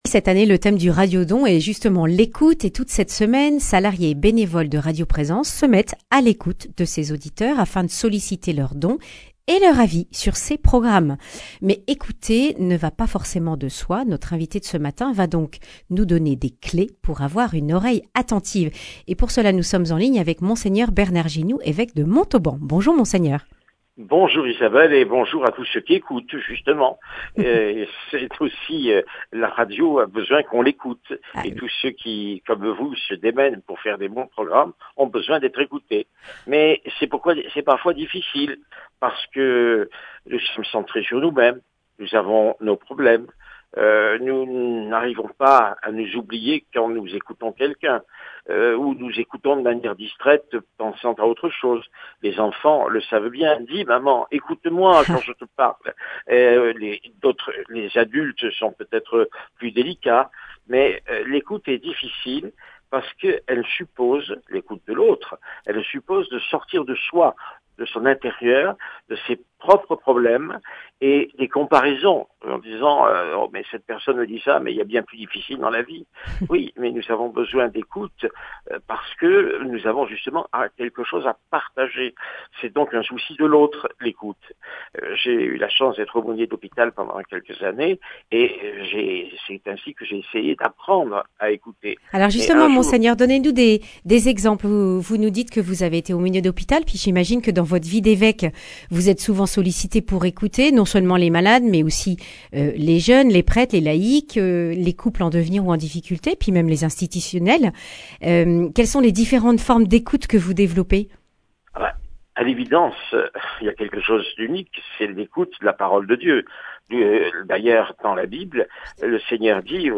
Monseigneur Bernard Ginoux, évêque de Montauban, encourage les auditeurs à donner pendant cette semaine de radio-don.